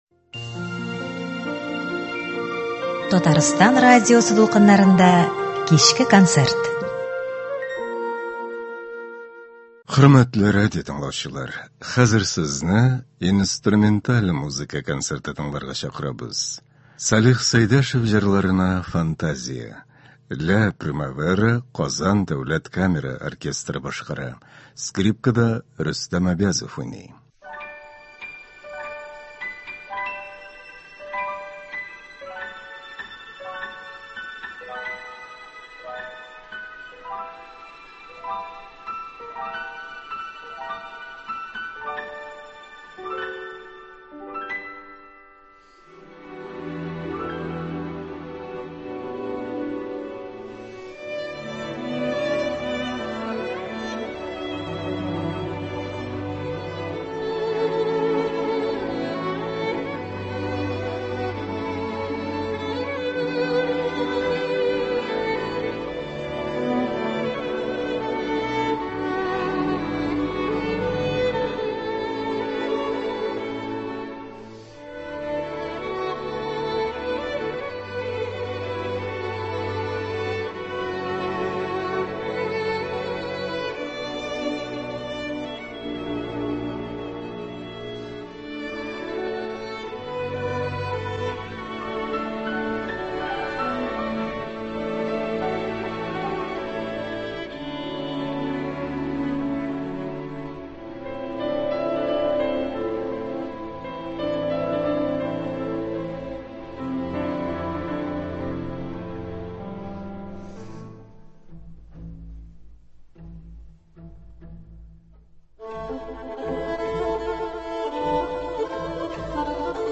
Инструменталь музыка концерты (18.07.23)